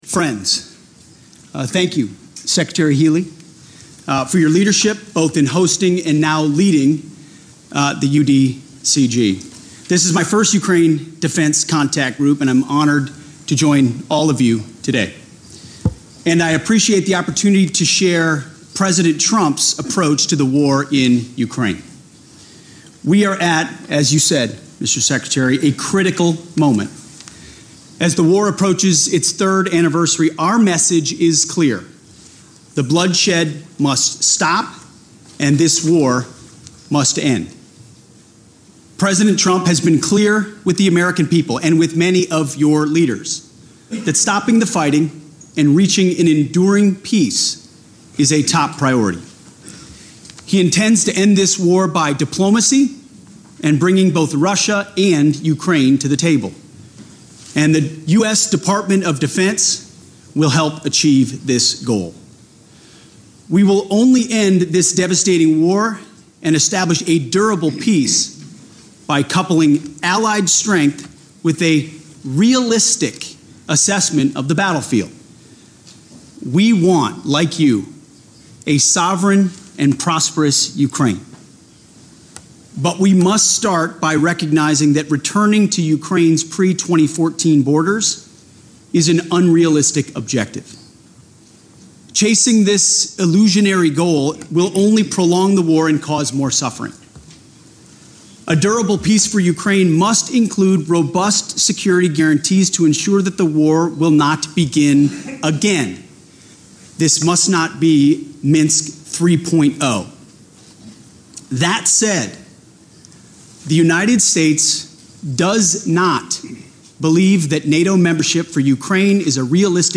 Opening Remarks at the 26th Iteration of the Ukraine Defense Contact Group Meeting
delivered 12 February 2025, NATO HQ, Brussels, Belgium